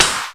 EMX SNR 8.wav